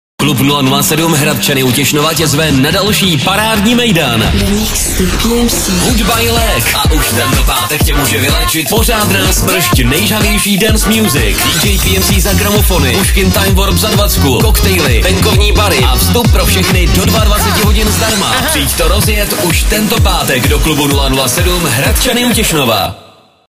pravidelná taneční párty Freeradia 107 FM - Le Mix s pořádnou porcí nejžhavějších hitů